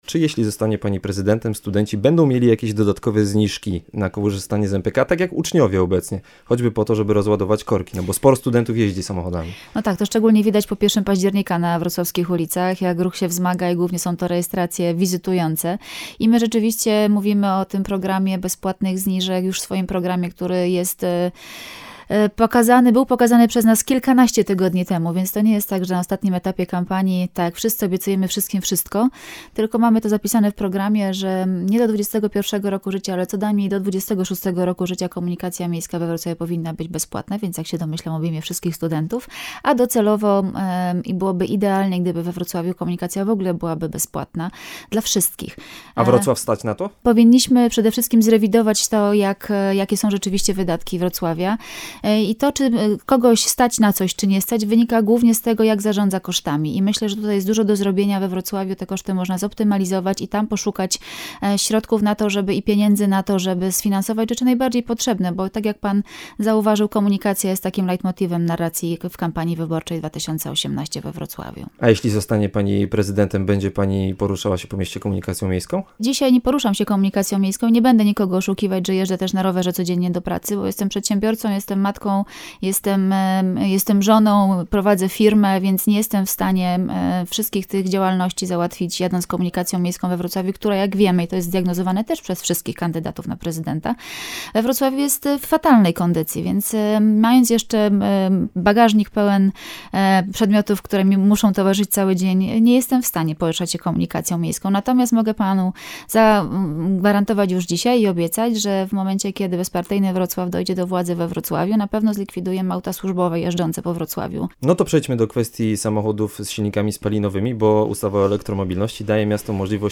Przez cały tydzień na naszej antenie (Magazyn Popołudniowy, pn.-pt. godz. 15-17) prezentować będziemy rozmowy z kandydatami i kandydatkami do objęcia stanowiska prezydenta Wrocławia. Każdy wywiad znajdziecie także poniżej.